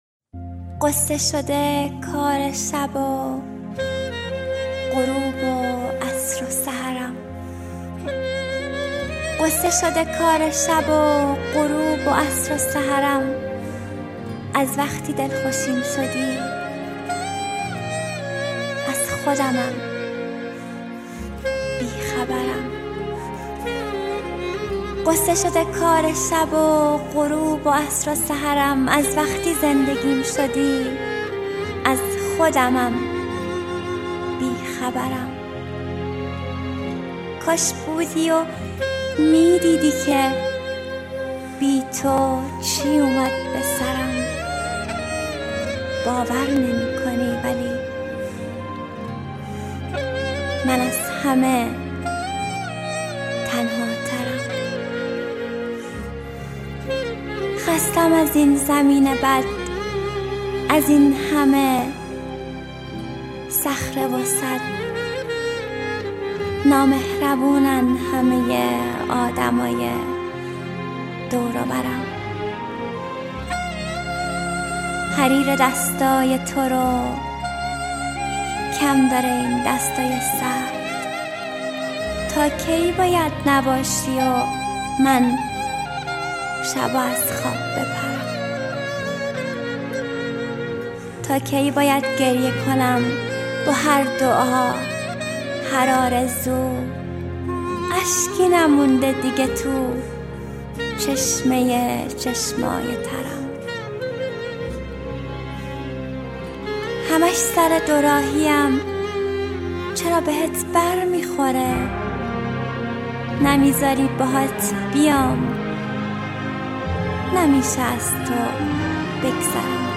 دانلود دکلمه من از همه تنهاترم با صدای مریم حیدرزاده